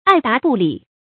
爱答不理 ài dā bù lǐ
爱答不理发音
成语注音 ㄞˋ ㄉㄚˊ ㄅㄨˋ ㄌㄧˇ